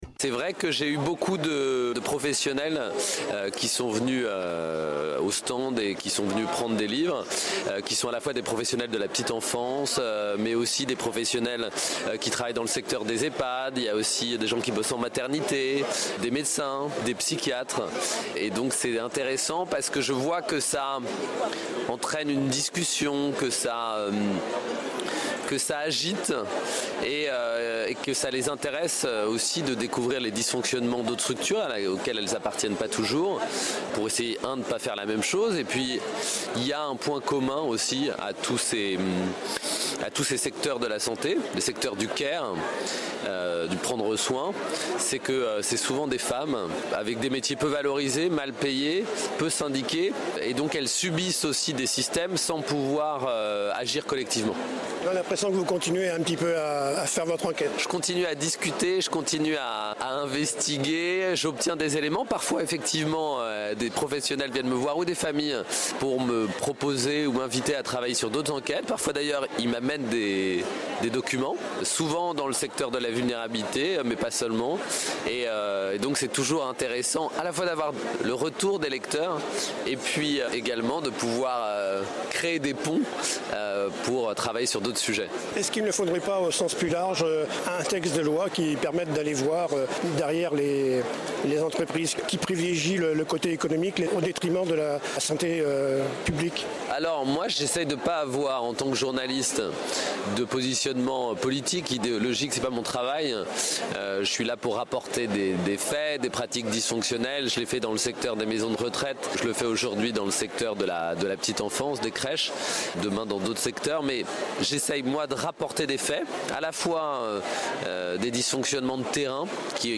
Retour sur la Foire du Livre de Brive 2024 avec le journaliste d’investigation Victor Castanet, auteur des livres Les Fossoyeurs et Les Ogres qui a dévoilé des scandales dans des ehpads et des crèches privées, a rencontré sur son stand de nombreuses personnes qui travaillent dans ces domaines de la santé…
Interview audio)